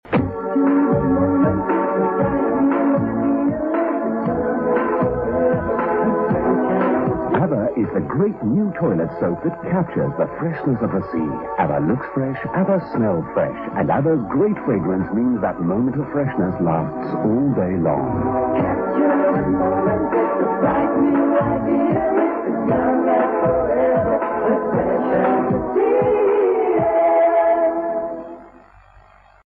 TV Commercial From the ‘90s.